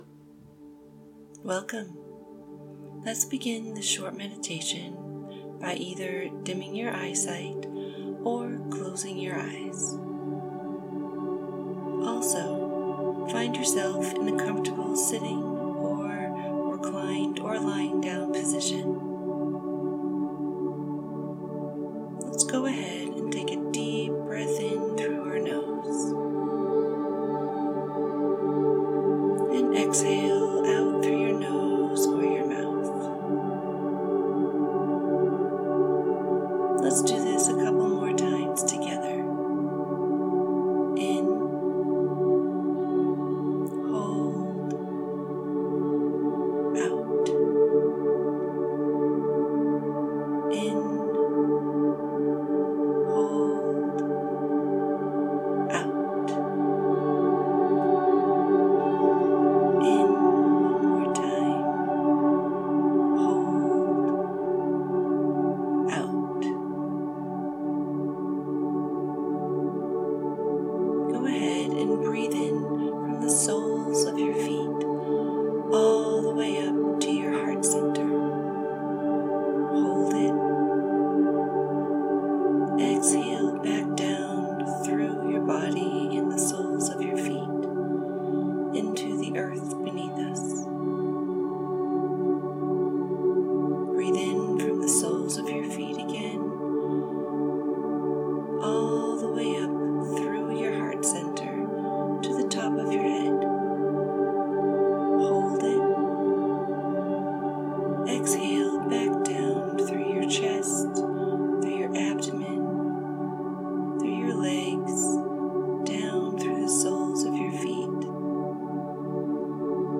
The Many Faces Of Pain - A Poem Read & Meditation